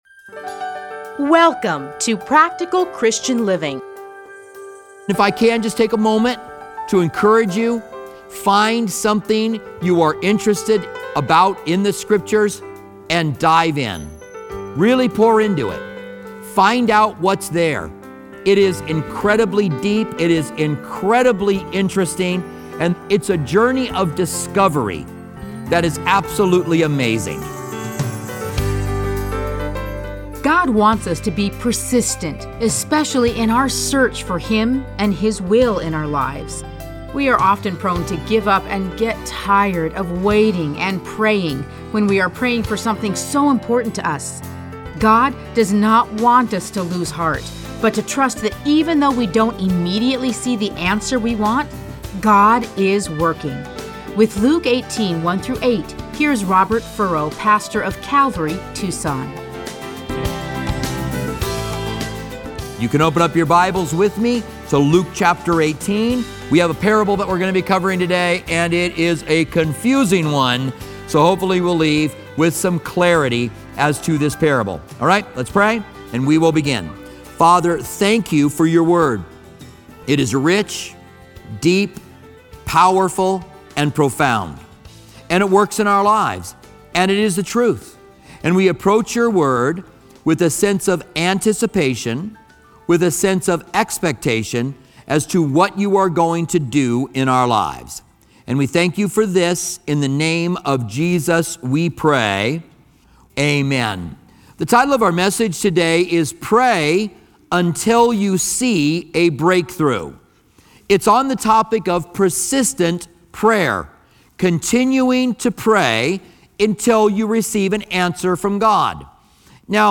Listen to a teaching from Luke 18:1-8.